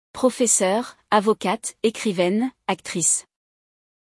No episódio de hoje, temos um diálogo especial para o Dia da Mulher. Vamos ouvir uma mãe e sua filha conversarem sobre as mulheres poderosas que há em sua família.